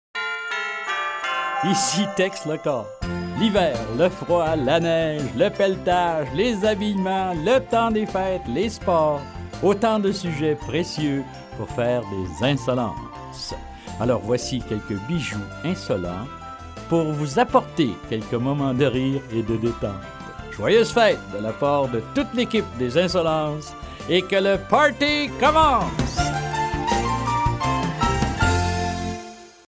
Diffusion distribution ebook et livre audio - Catalogue livres numériques
Version temps des fêtes Lu par Tex Lecor Durée : 1h04 14 , 40 € Ce livre est accessible aux handicaps Voir les informations d'accessibilité